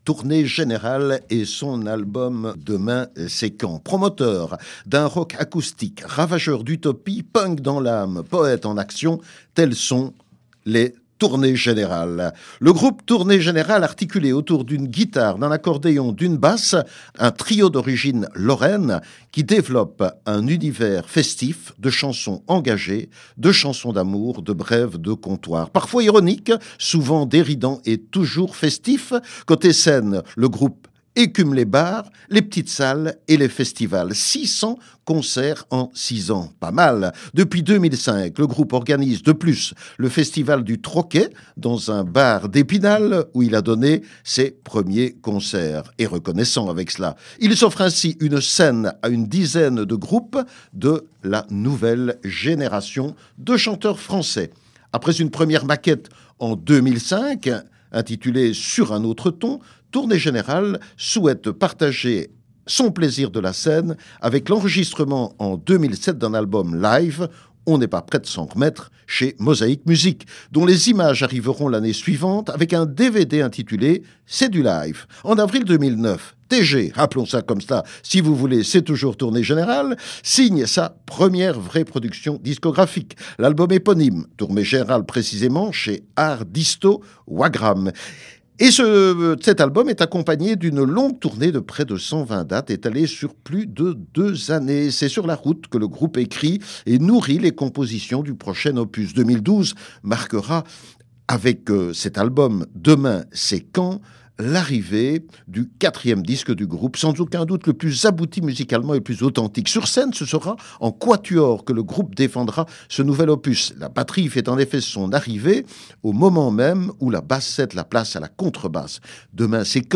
rock acoustique ravageur d’utopie
punks dans l’âme et poètes en action.